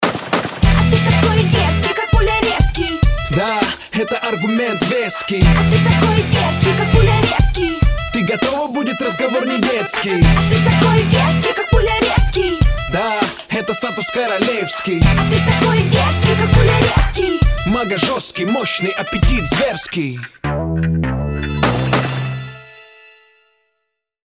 • Качество: 320, Stereo
веселые
кавказские
качает
дерзкий